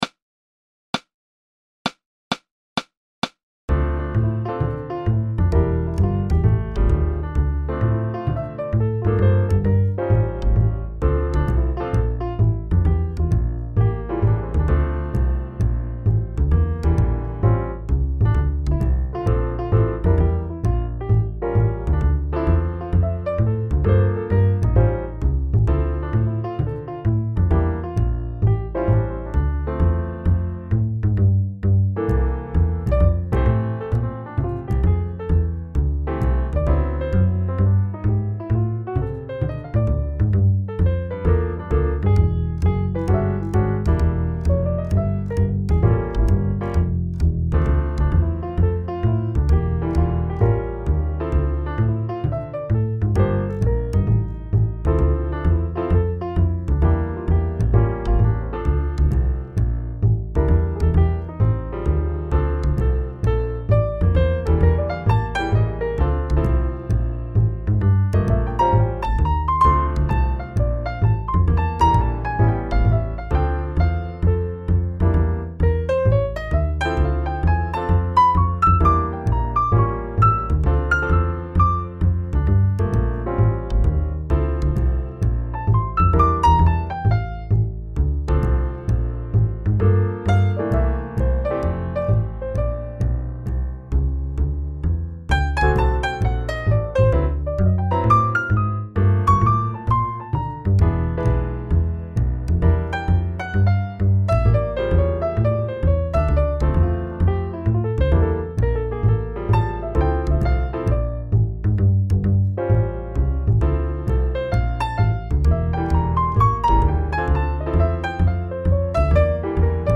Um den Kontrabass und den Flügel aus der Essential Instrument Collection von Ableton Live 6 zu testen, habe ich mal ein altes (von Band in a box generiertes) Midifile ausgegraben.
Das eignet sich prima als Playalong, darum stelle ich es hier mal in 4 verschiedenen Tempi zur Verfügung. Den Bass habe ich etwas lauter als üblich gemacht und dafür den Klick weggelassen.
Swing131.mp3